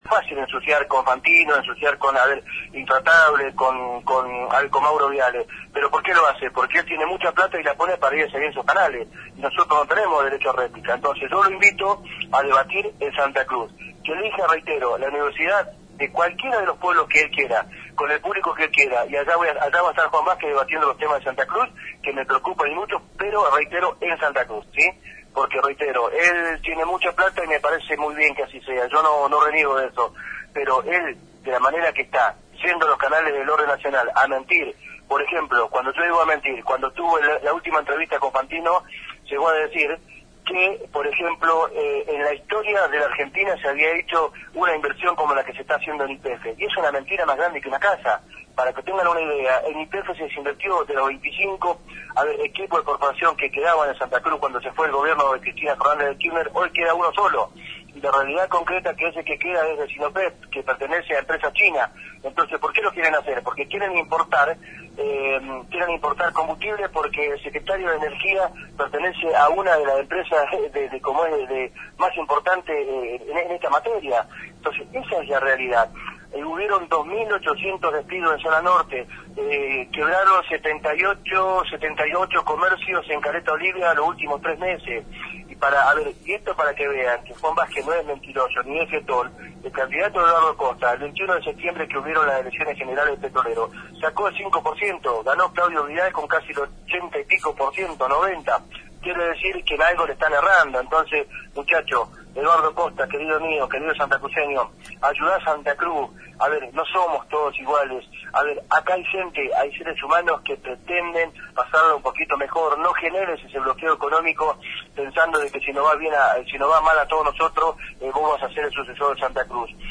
En dialogo con LRA 59 Radio Nacional, el intendente de Gobernador Gregores y candidato a diputado nacional por el Frente Para la Victoria SantacruceAi??o, Juan Benedicto VA?zquez, desafiA? al actual diputado nacional y candidato a senador nacional por UniA?n Para Vivir Mejor-Cambiemos, Eduardo Costa, a debatir de cara a las elecciones legislativas del prA?ximo 22 de octubre, “en Santa Cruz” y no en los medios de comunicaciA?n de Buenos Aires, donde afirmA? que no tiene derecho a rAi??plica.